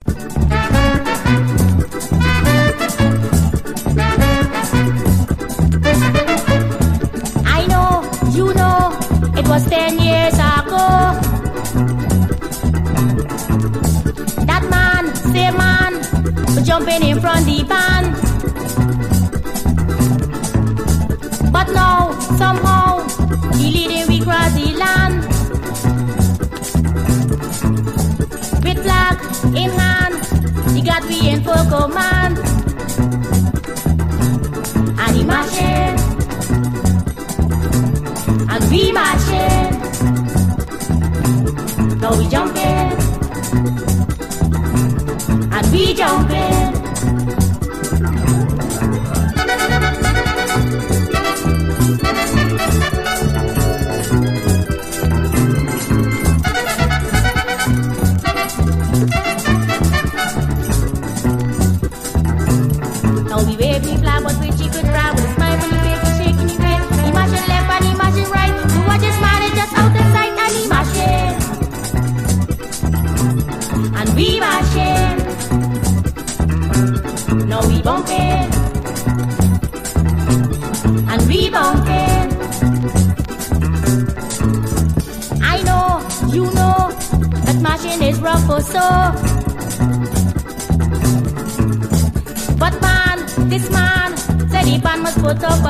CARIBBEAN, 7INCH
ガーリー・ヴォーカルが愛想を振りまく、キュートなダンサブル・カリプソ！
ブラス＆オルガンもニクい！